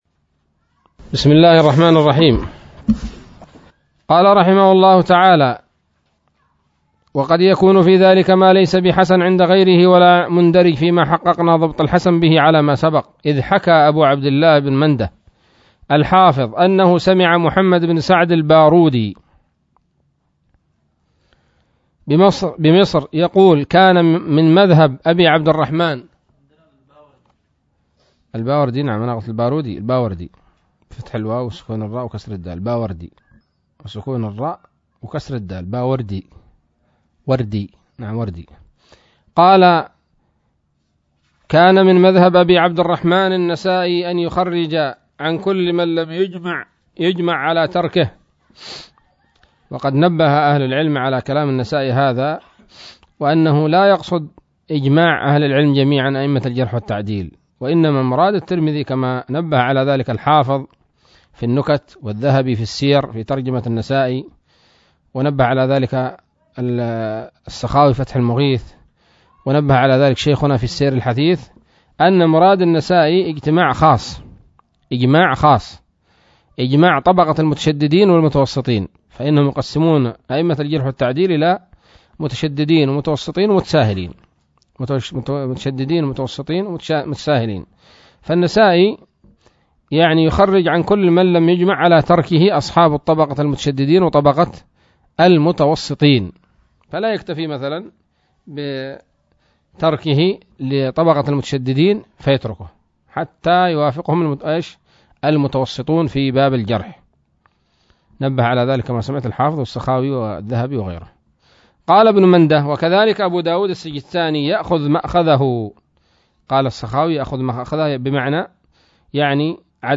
الدرس السادس عشر من مقدمة ابن الصلاح رحمه الله تعالى